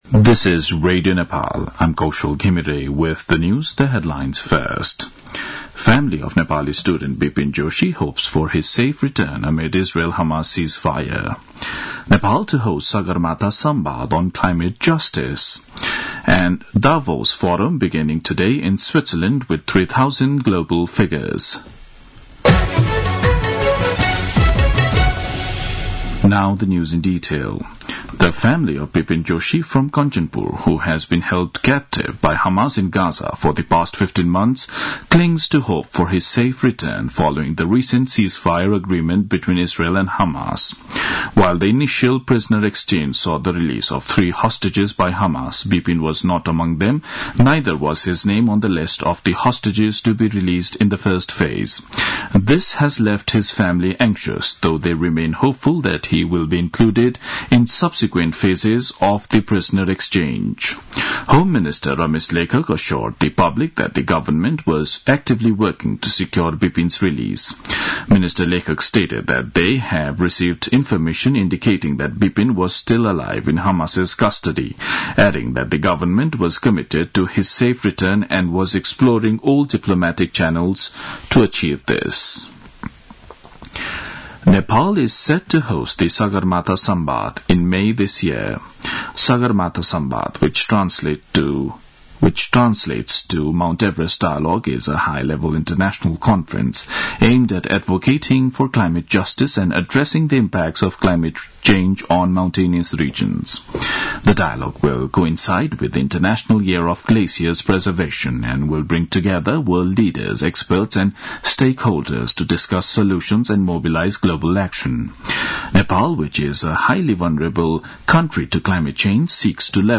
दिउँसो २ बजेको अङ्ग्रेजी समाचार : ८ माघ , २०८१